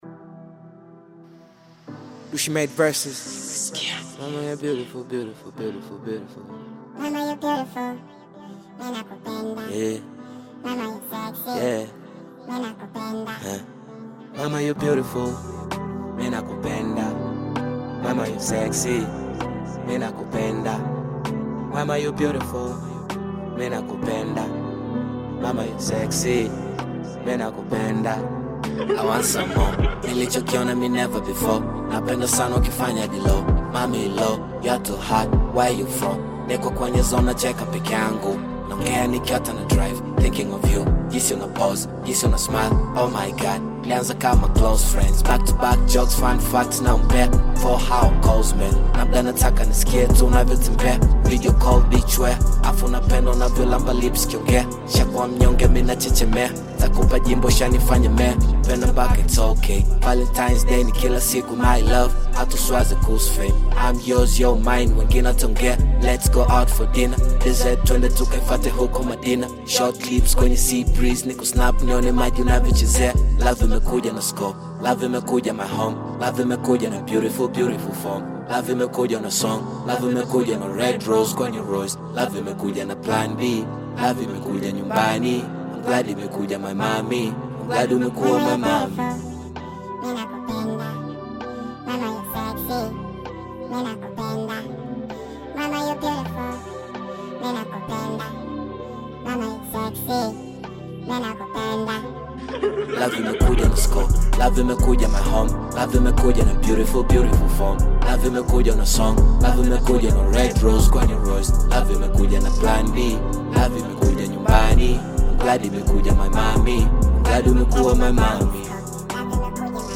Bongo Flava music track
Tanzanian artist, singer, and songwriter